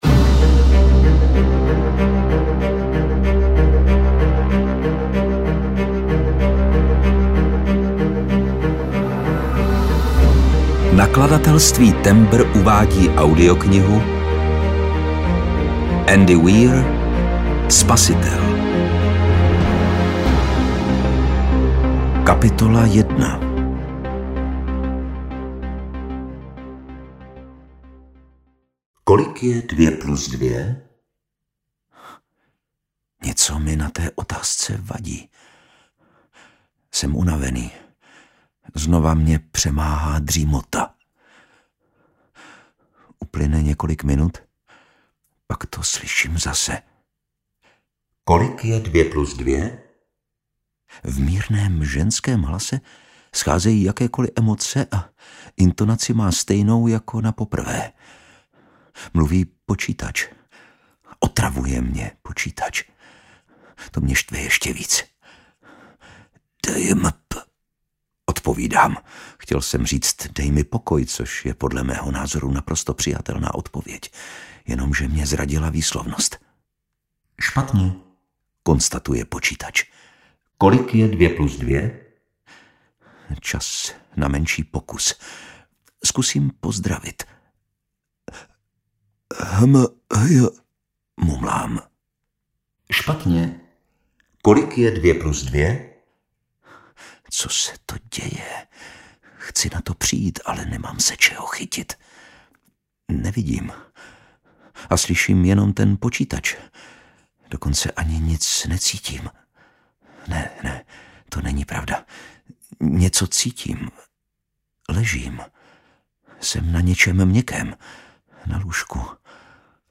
Spasitel audiokniha
Ukázka z knihy
• InterpretLukáš Hlavica